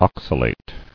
[ox·a·late]